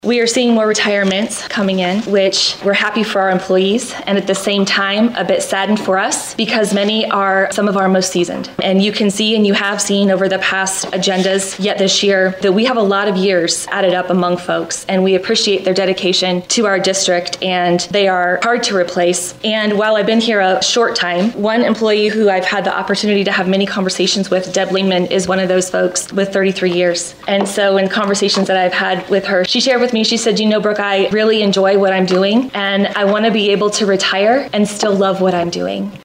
Celina Board of Education meeting for April